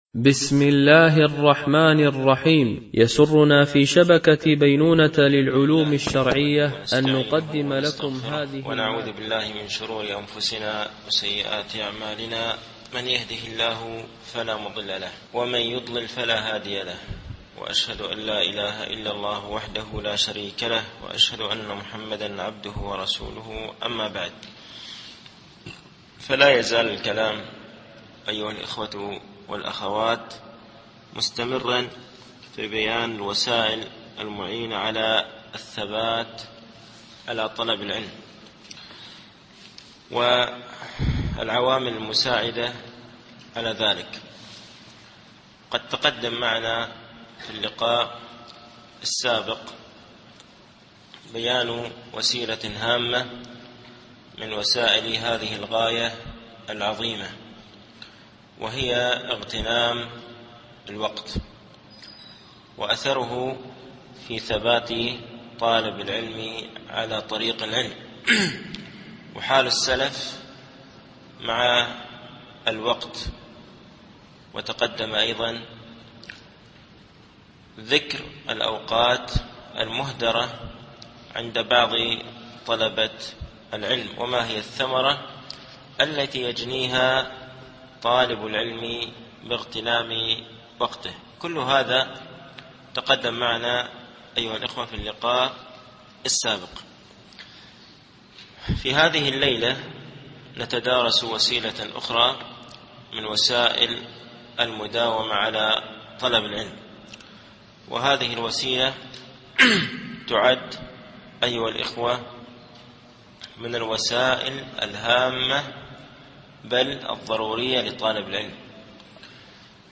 التعليق على كتاب معالم في طريق طلب العلم (الوصية 39 الاستمرار على طلب العلم 6) - الدرس التاسع و السبعون